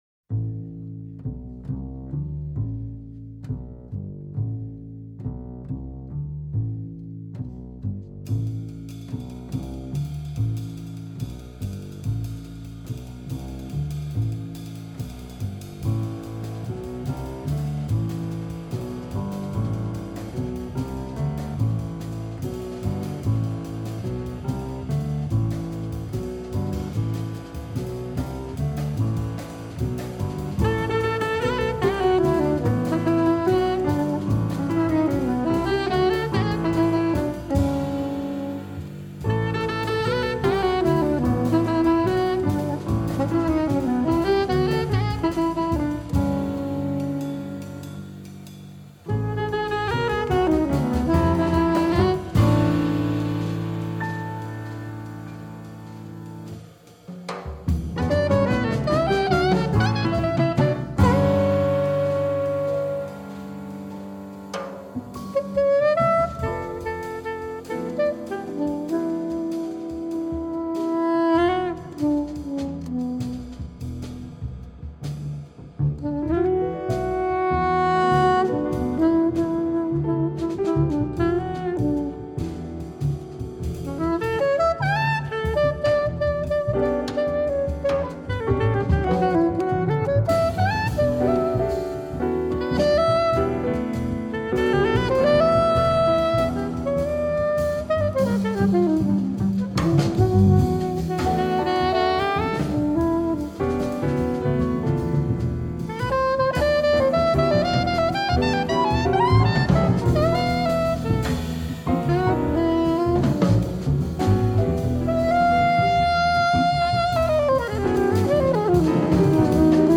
Contrabas
Piano
Slagwerk
Sopraansaxofoon